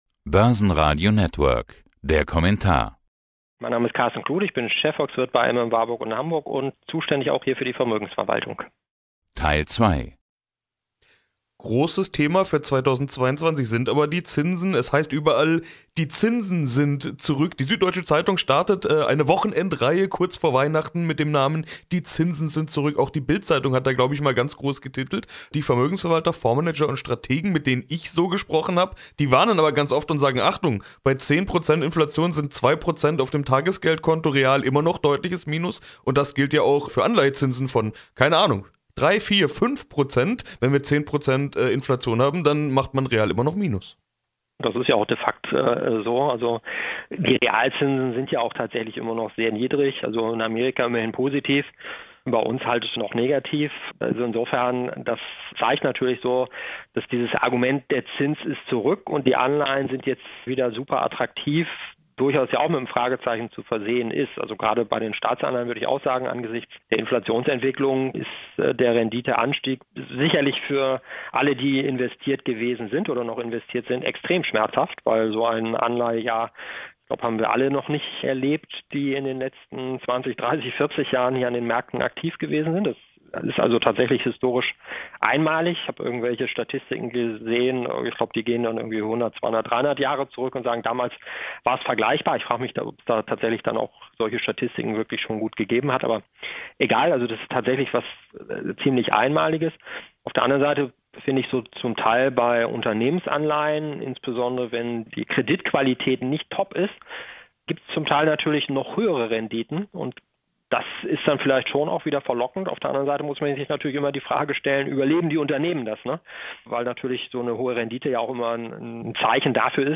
Downloads Zum Interview